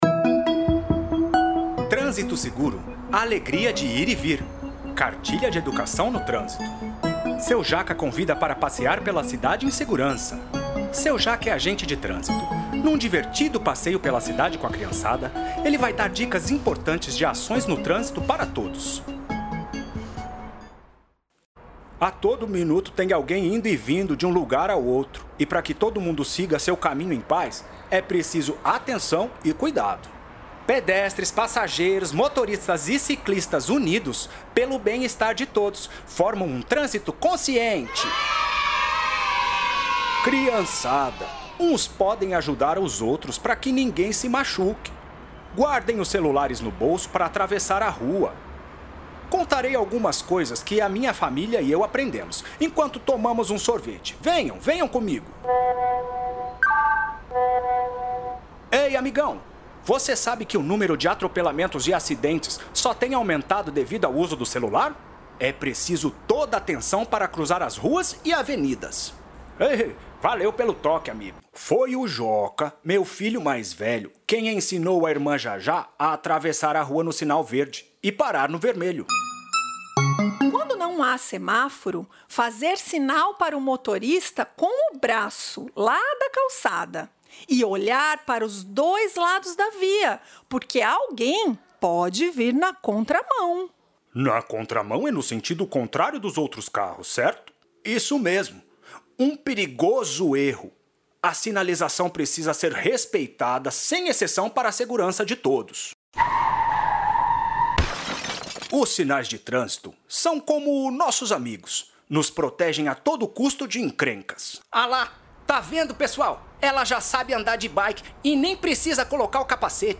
Os materiais estão disponíveis no canal do Youtube da Prefeitura de Hortolândia e nos links abaixo: Baixar anexos: Cartilha Trânsito Seguro Áudio Descrição da Cartilha Trânsito Seguro voltar ao topo